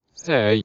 swordman_select4.wav